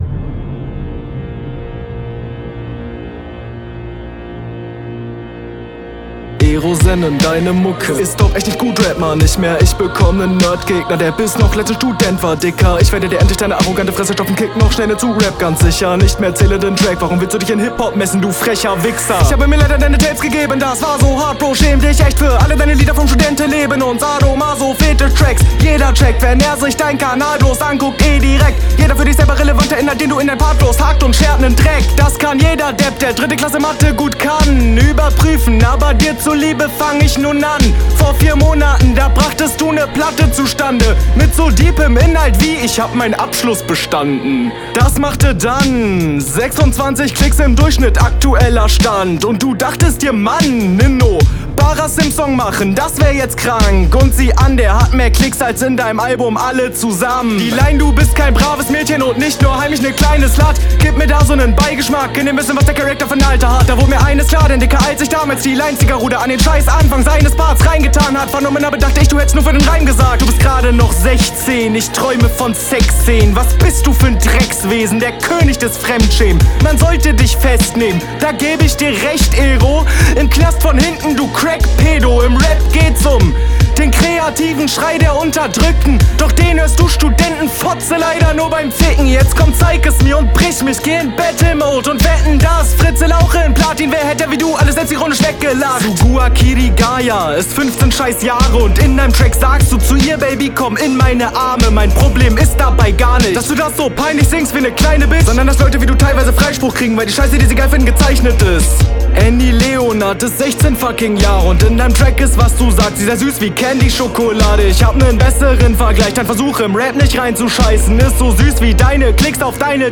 starker Beat und absolut starker Text.
Der Beat steht dir richtig richtig gut, auch genau mein Geschmack.